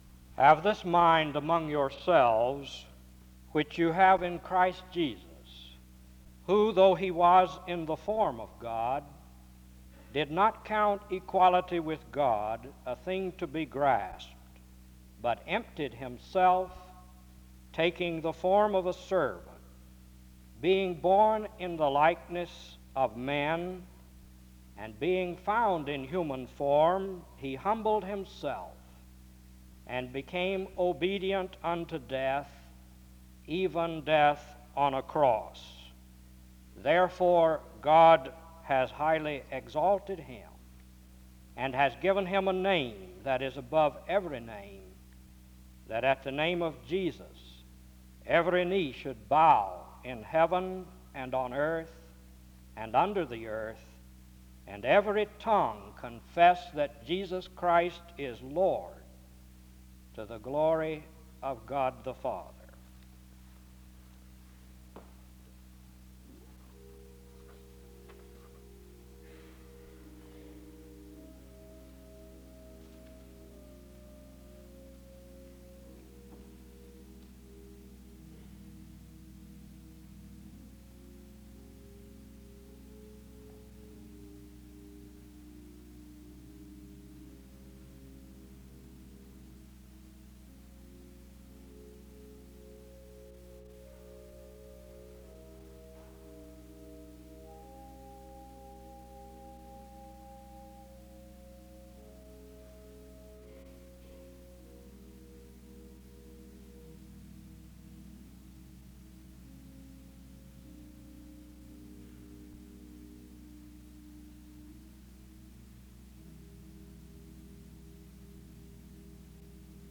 The service starts with a scripture reading from 0:00-1:03. Music plays from 1:05-5:49. A prayer is offered from 5:56-8:38.
An introduction to the speaker is given from 8:43-10:40.